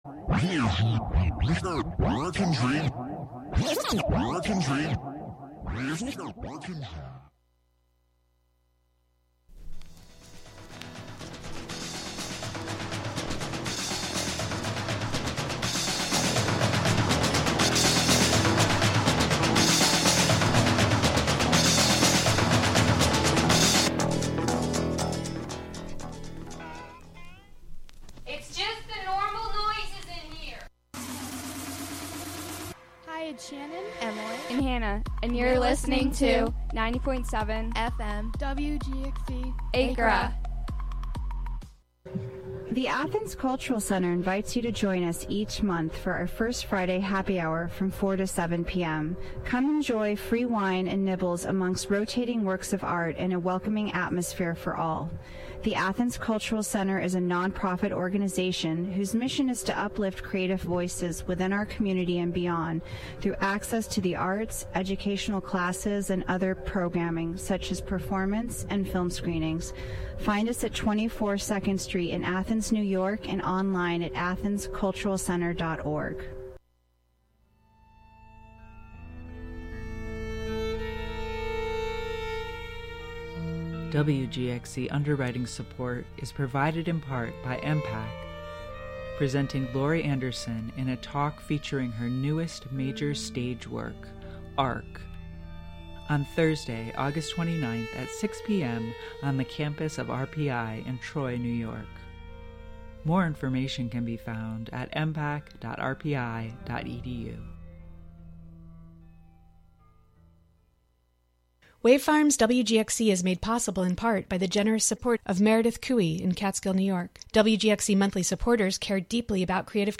"Beakuency" invites the local community to be inspired by the joy, beauty, and wisdom bird enthusiasts find in the nature of our neighborhoods. Every episode features an interview with local bird people, plus a freeform mix of sound made by birds and humans inspired by birds, and “Birds of Wave Farm,” a field recording journal from Wave Farm, in Acra, New York.